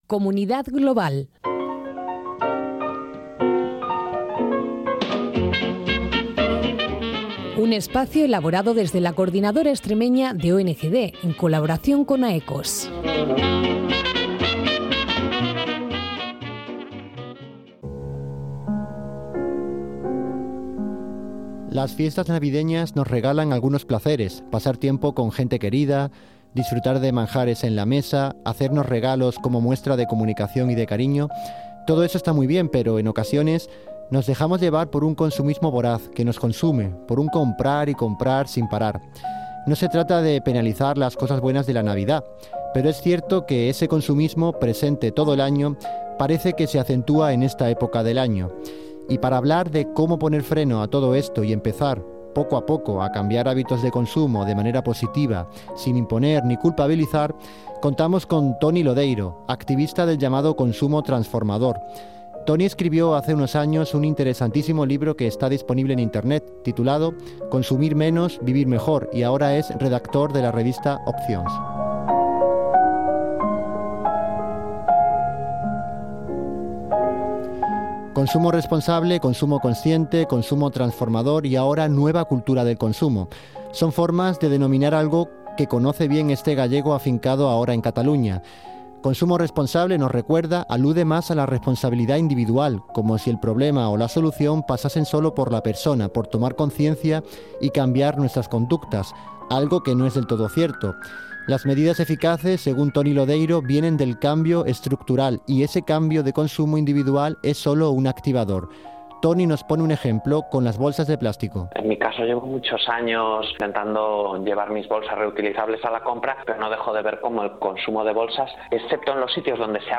Consumo Responsable, Consumo Transformador (Entrevista de Radio) - CONGDEX - Coordinadora Extremeña de ONGs